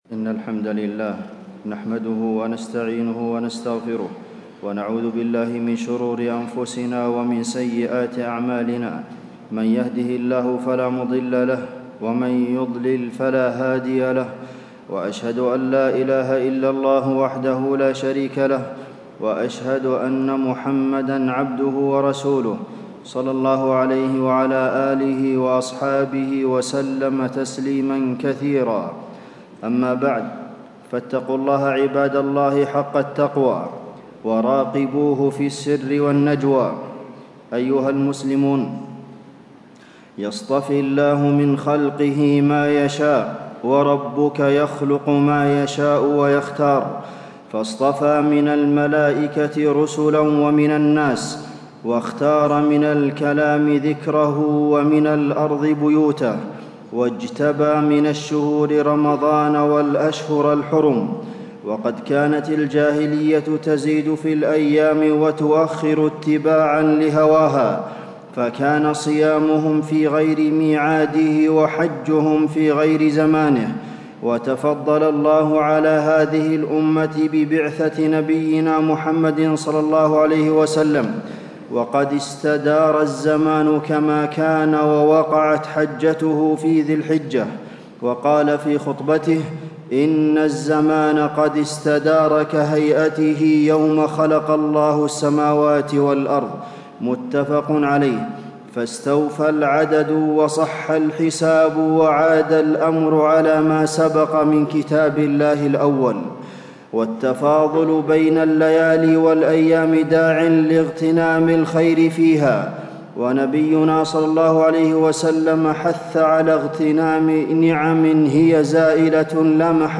تاريخ النشر ٢٧ ذو القعدة ١٤٣٦ هـ المكان: المسجد النبوي الشيخ: فضيلة الشيخ د. عبدالمحسن بن محمد القاسم فضيلة الشيخ د. عبدالمحسن بن محمد القاسم أعمال أيام العشر من ذي الحجة